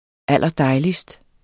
Udtale [ ˈalˀʌˈdɑjlisd ]